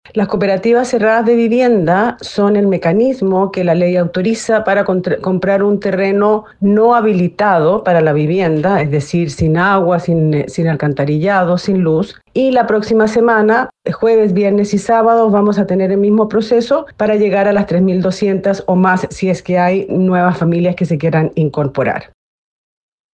Así lo señaló Gloria Maira, la delegada ministerial del Minvu designada especialmente para este proceso.